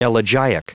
Prononciation du mot elegiac en anglais (fichier audio)
elegiac.wav